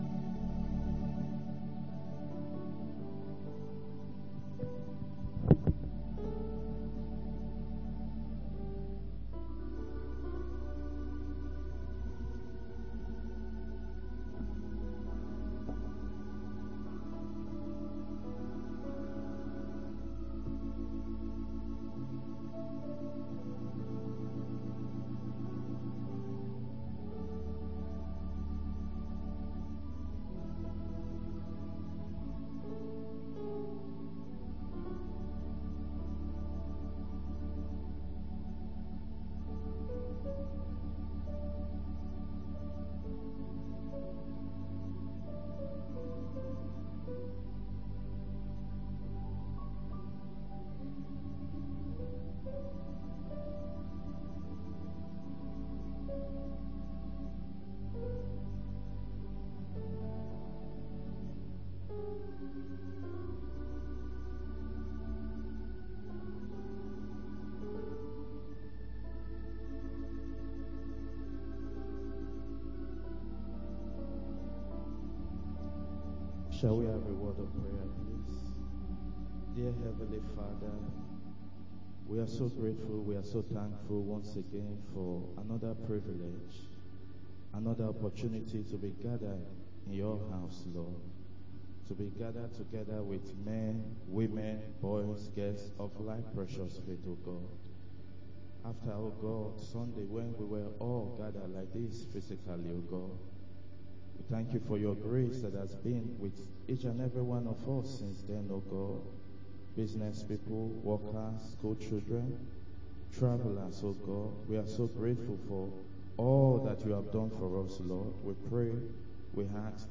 Wed. Prayer Meeting